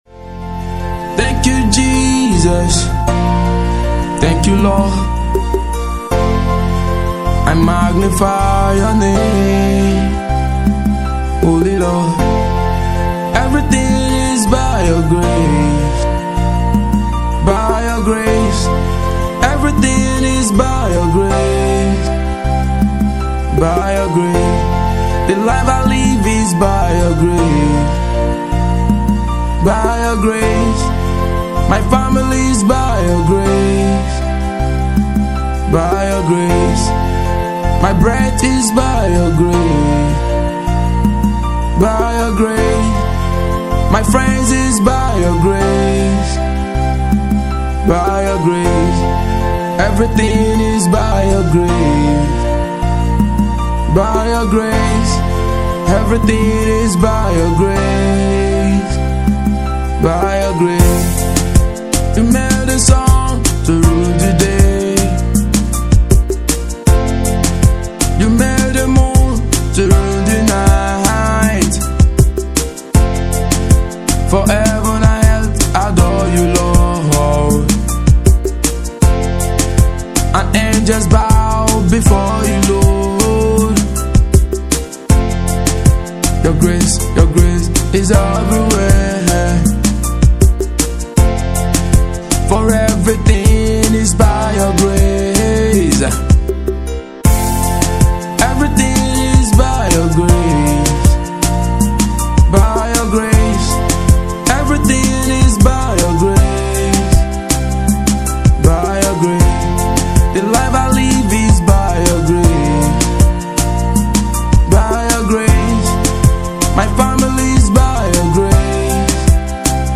a soul-lifting gospel song
With heartfelt vocals and a worshipful melody